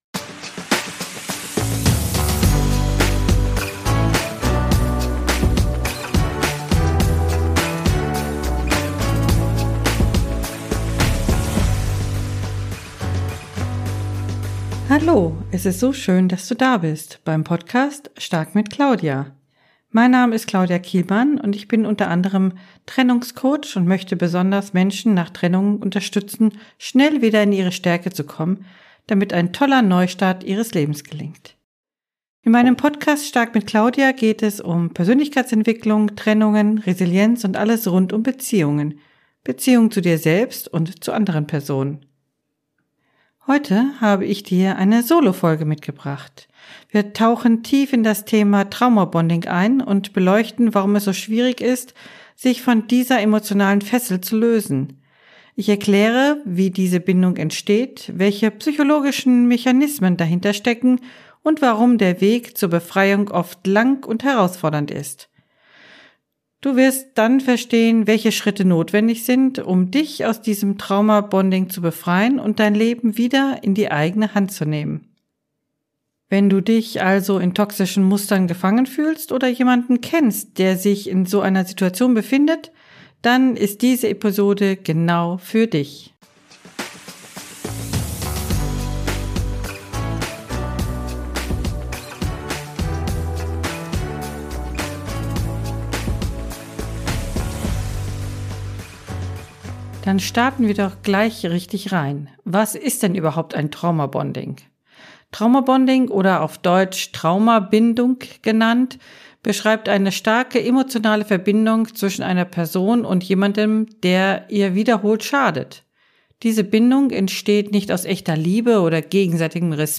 Heute habe ich dir eine Solofolge mitgebracht: Wir tauchen tief in das Thema Trauma Bonding ein und beleuchten, warum es so schwierig ist, sich von dieser emotionalen Fessel zu lösen. Ich erkläre, wie diese Bindung entsteht, welche psychologischen Mechanismen dahinterstecken und warum der Weg zur Befreiung oft lang und herausfordernd ist.